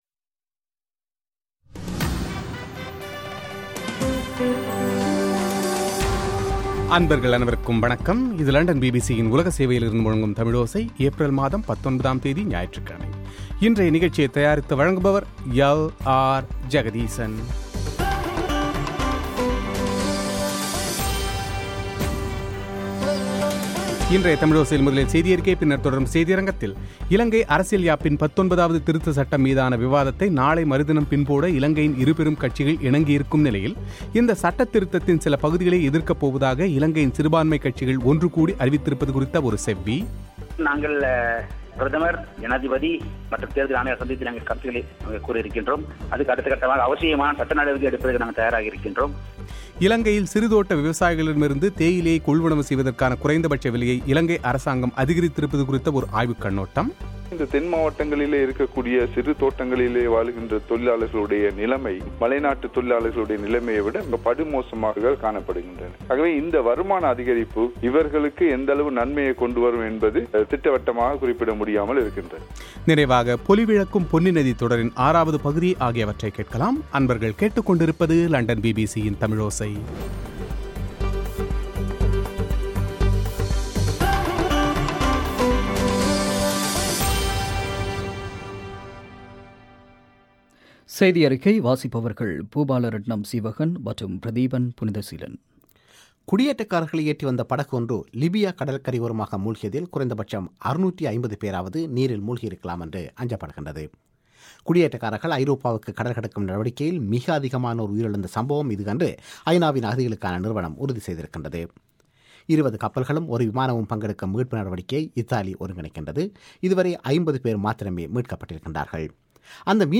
இலங்கை அரசியல்யாப்பின் 19ஆவது திருத்தச் சட்டம் மீதான விவாதத்தை நாளை மறுதினம் பின்போட இலங்கையின் இருபெரும் கட்சிகள் இணங்கியுள்ளது குறித்த செய்திகள்;